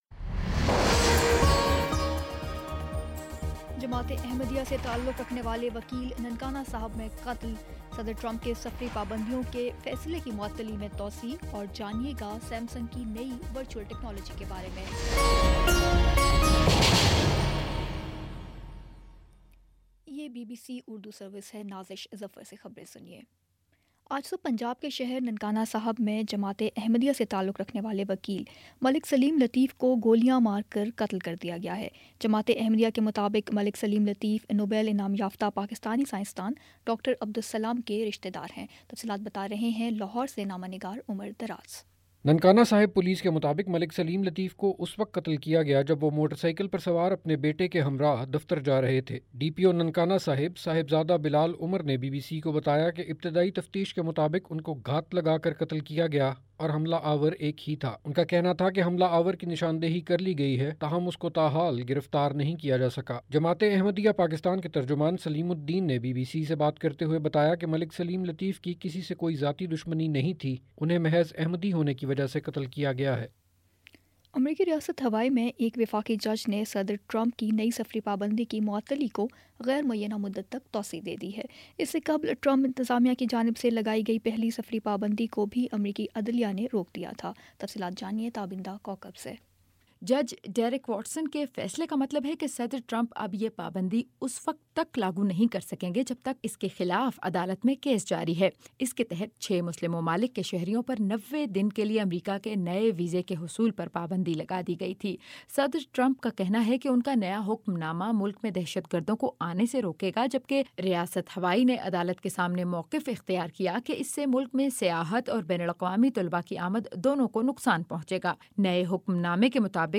مارچ 30 : شام چھ بجے کا نیوز بُلیٹن